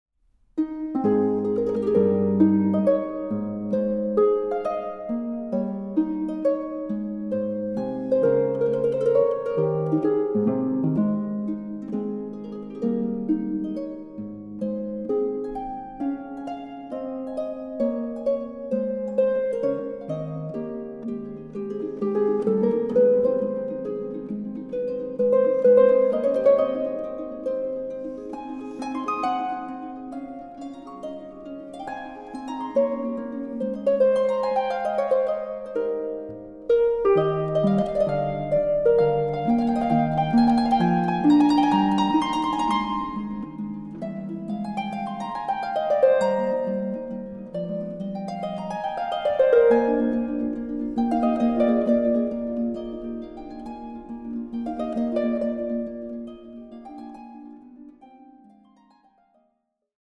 Auch in Dolby Atmos
Harfe
Aufnahme: Festeburgkirche Frankfurt, 2024